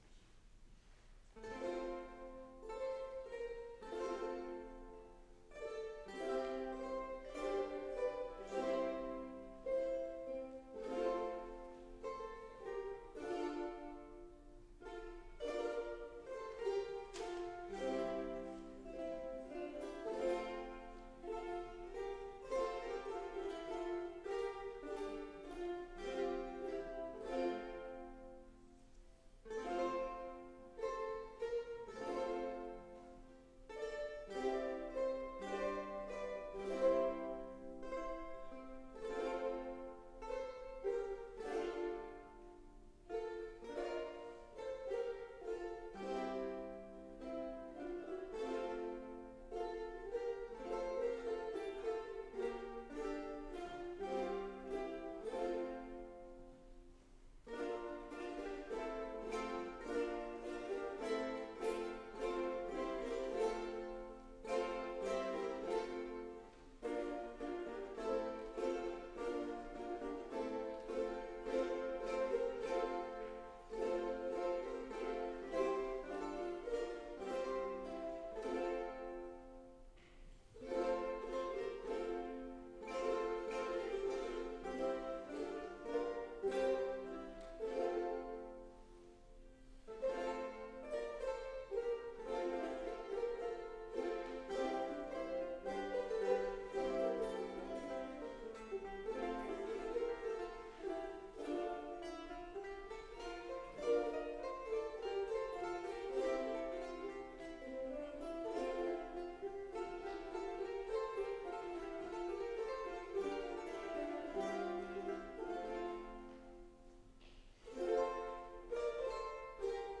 プログラムの最初の二曲は全体演奏で、私もルネサンスギターで参加したのですが、その時の録音をどうぞ。
ルネサンスギター４本、ウクレレ5、6本、歌は大体10人ほどという構成。ウクレレ経験は２、3年以内の初心者が大多数。